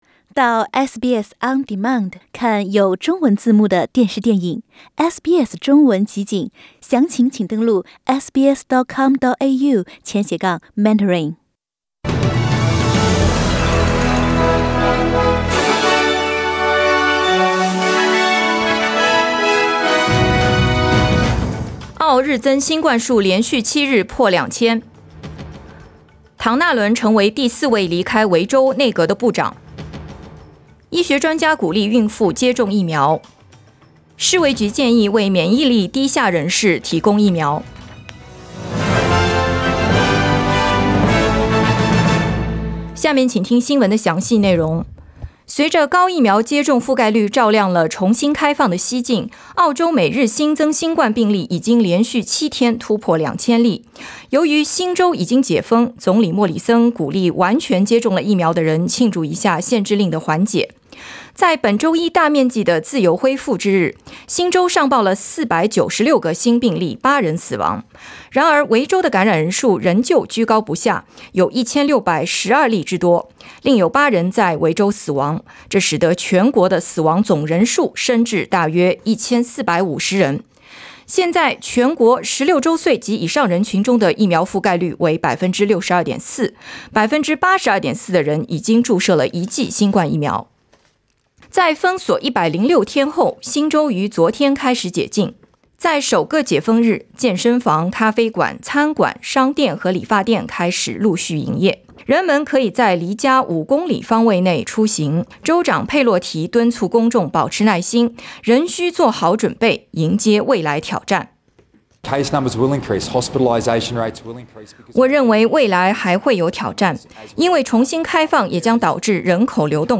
SBS早新聞（2021年10月12日）
SBS Mandarin morning news Source: Getty Images